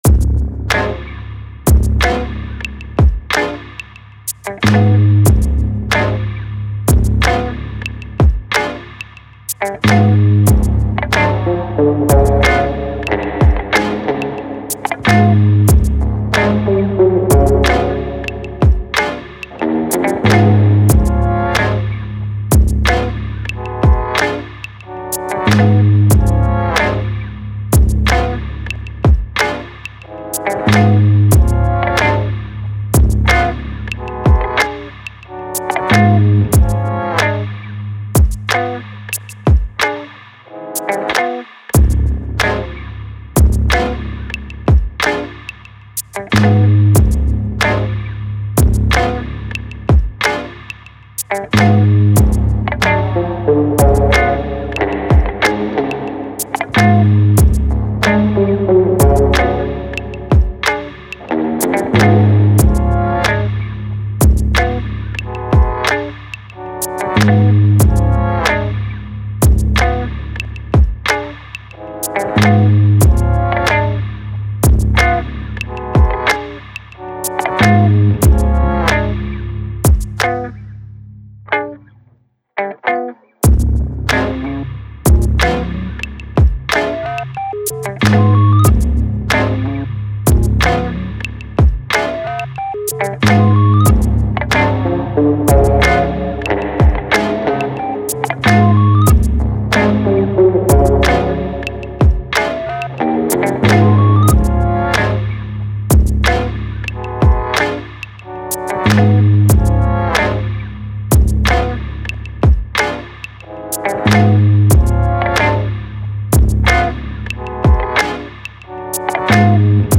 Spooky low slung bass with oddball accents and beats.